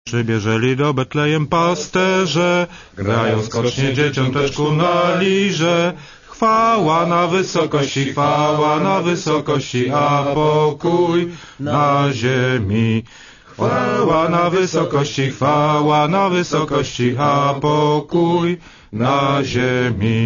Po emocjonującej dyskusji i tradycyjnych sporach politycy zaśpiewali wspólnie kolędę.
Posłuchaj kolędy w wykonaniu gości 7. Dnia Tygodnia
Podkreślił należy, że najgłośniej śpiewał Jan Rokita z Platformy Obywatelskiej, a pozostali goście stworzyli sprawny chórek...
koleda.mp3